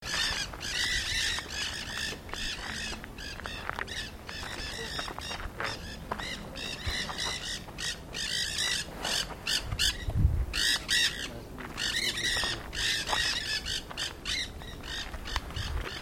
Ñanday (Aratinga nenday)
8 ejemplares juntos.
Nombre en inglés: Nanday Parakeet
Fase de la vida: Adulto
Localidad o área protegida: Reserva Ecológica Costanera Sur (RECS)
Condición: Silvestre
Certeza: Observada, Vocalización Grabada